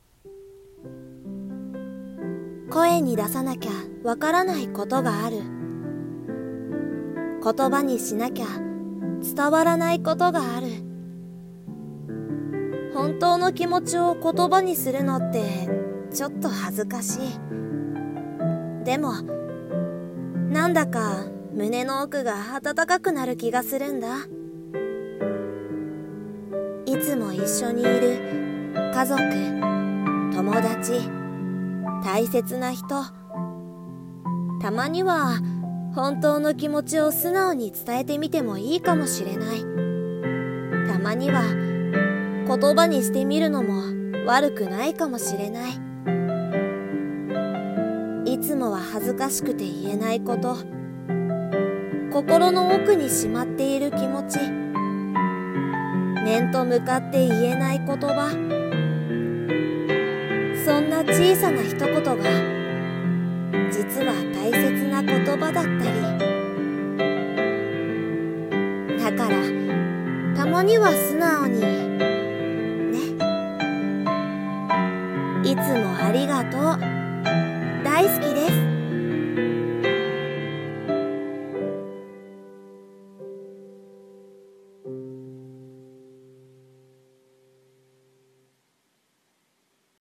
[声劇･朗読]たまには素直に